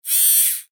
ロボットアーム1.mp3